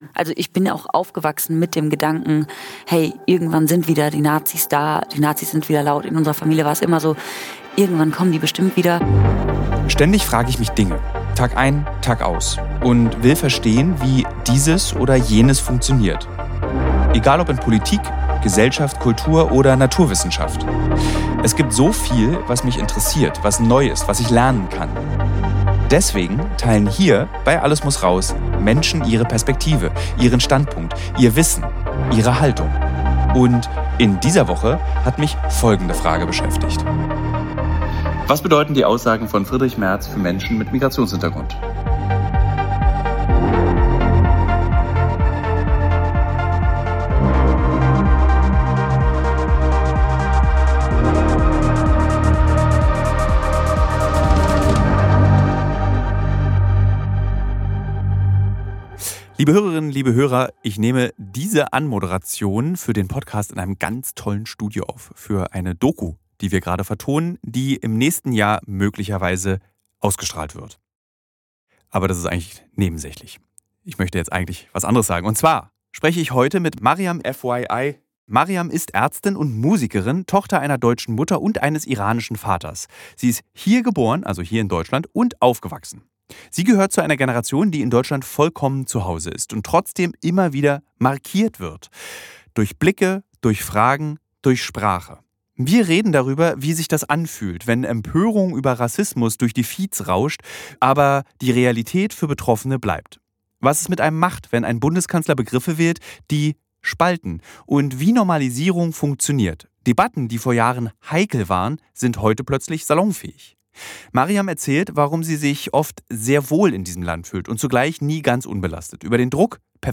Ein Gespräch über Zugehörigkeit, Sprache, Privilegien und die Frage, wie wir uns gegenseitig Halt geben können – in einer Zeit, in der Empörung schnell vergeht, aber Rassismus bleibt.